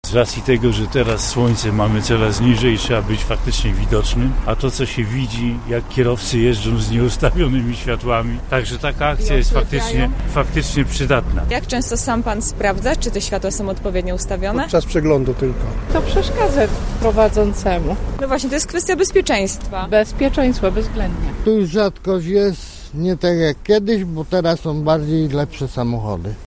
Zapytaliśmy kierowców jak często sprawdzają światła w swoich samochodach: